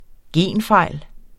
Udtale [ ˈgeˀn- ]